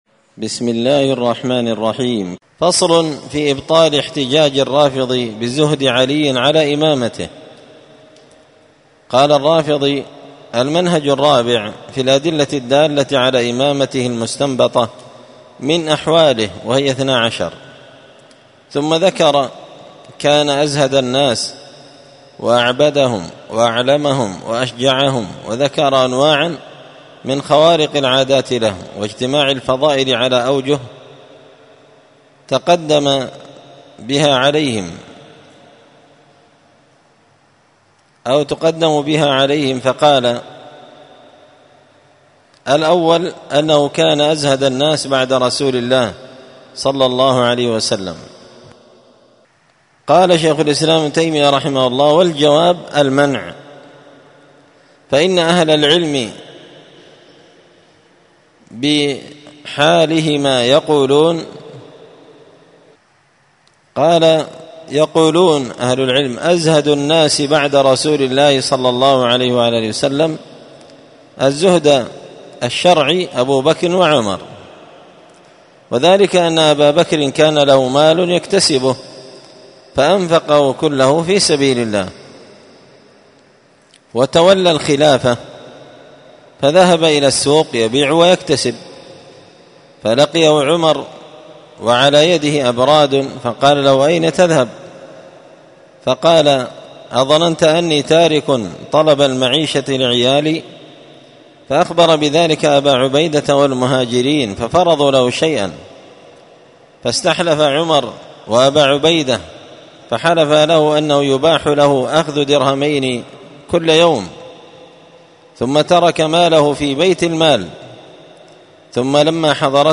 الأربعاء 28 صفر 1445 هــــ | الدروس، دروس الردود، مختصر منهاج السنة النبوية لشيخ الإسلام ابن تيمية | شارك بتعليقك | 86 المشاهدات